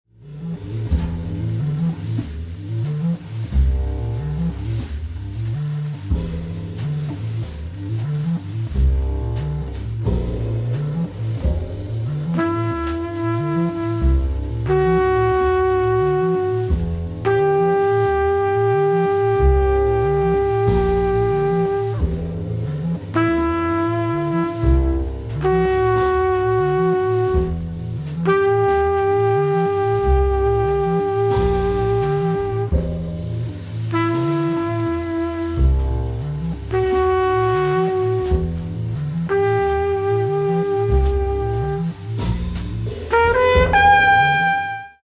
cor
bcl, cl